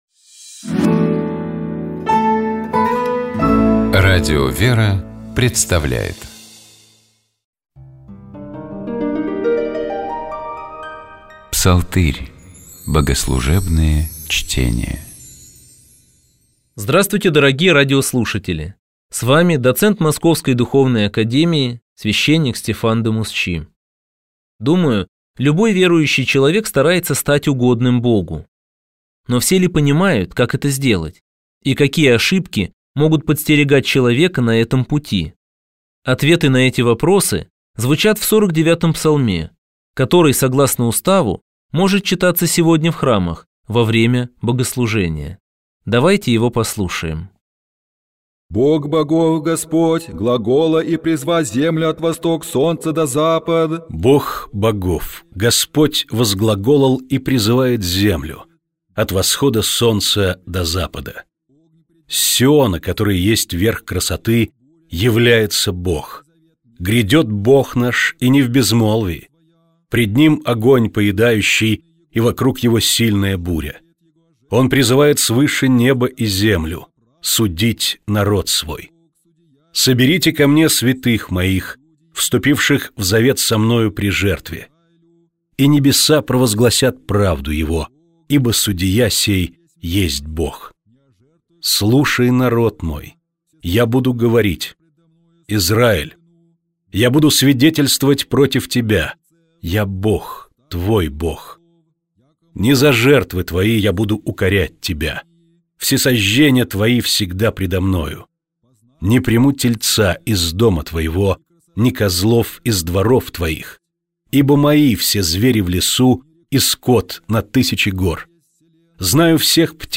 Псалом 115. Богослужебные чтения